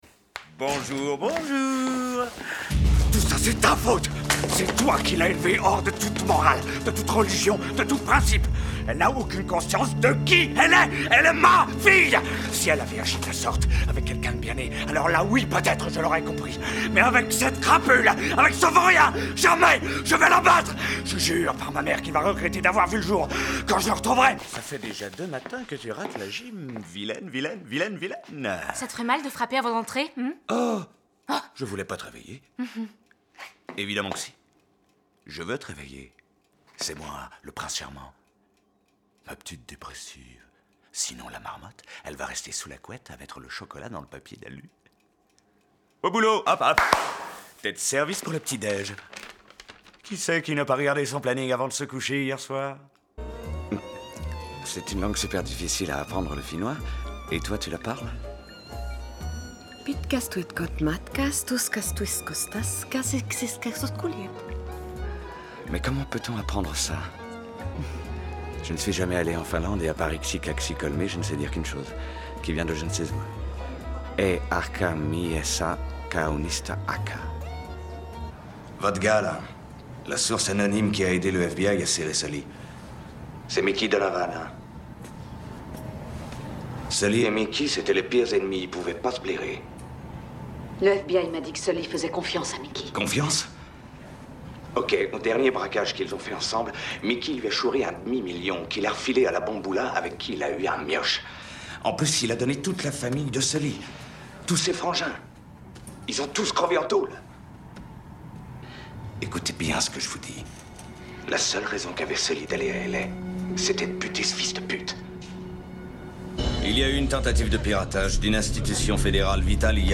Démo Doublage